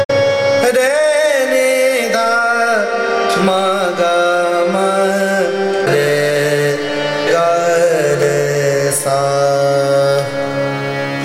Marwa (Avaroha)